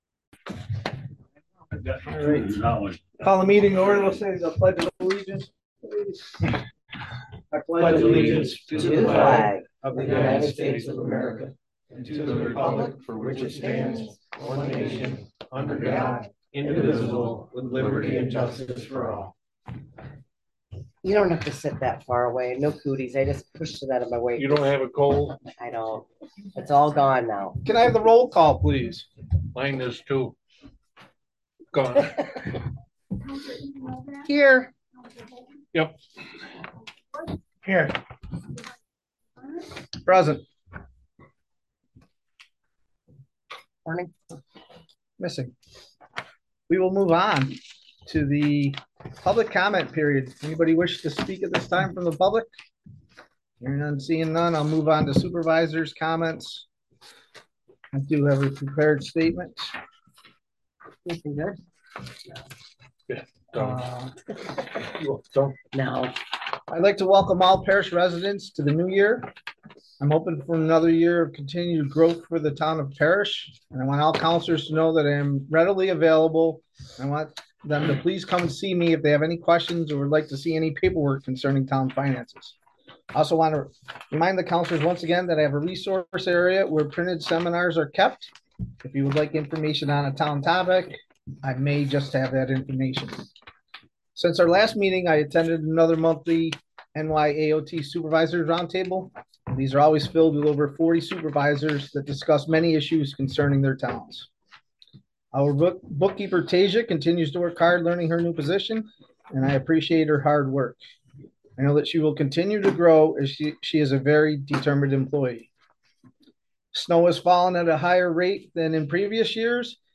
(Note: there was a glitch in the recording from about the 40 minute mark to about the 51 minute mark. This remains in the video recording, but was cut from the audio recording.)
Parish town board regular meeting audio